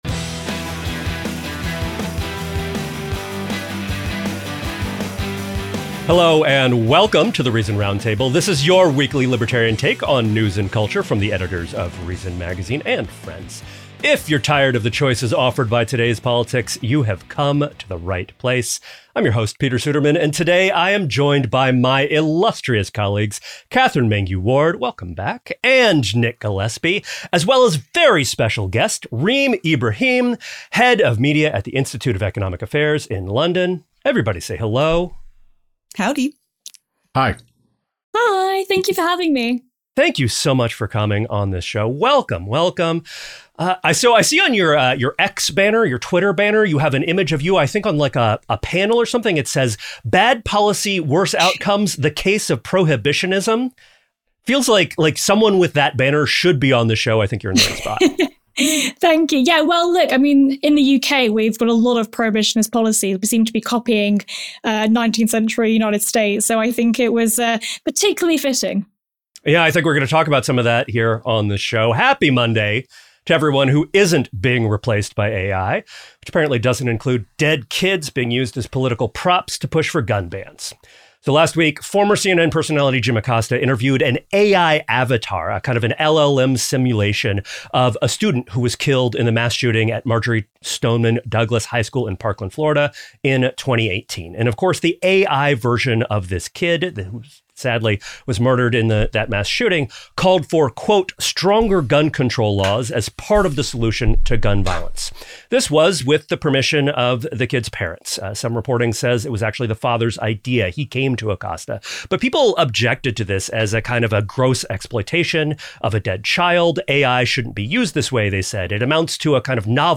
The Reason Roundtable